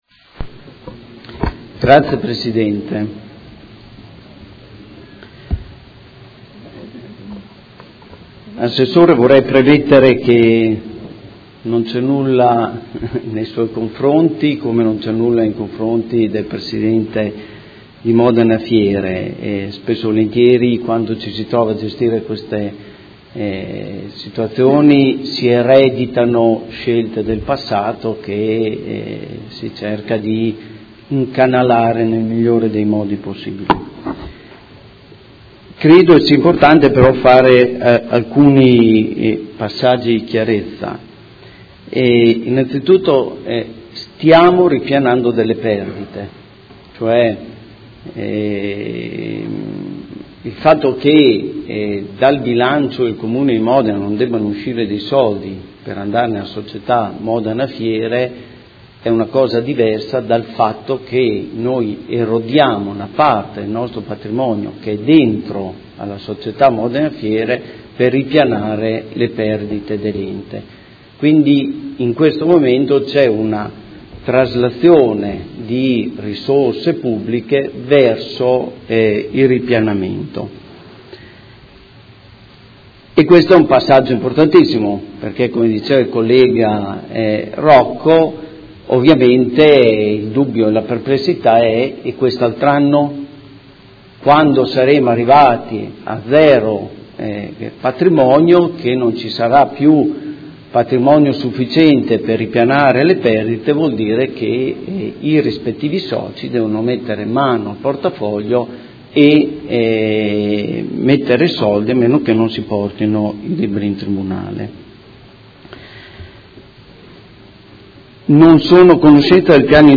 Antonio Montanini — Sito Audio Consiglio Comunale
Proposta di deliberazione: Modena Fiere srl – Proposta di riduzione del capitale sociale in conseguenza di perdite a norma dell’art. 2482bis del Codice Civile. Dibattito